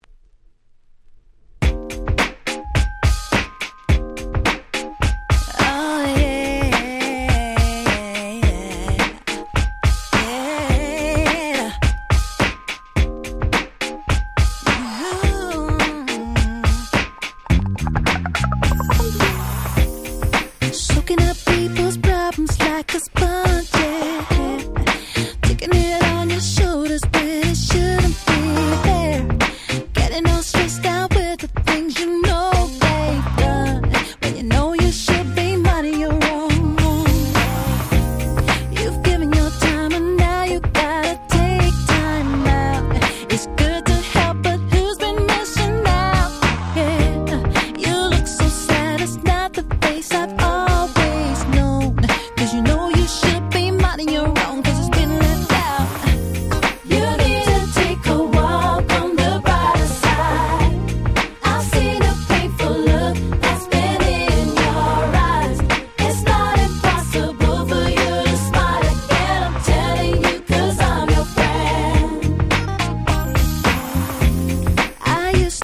02' Nice UK R&B !!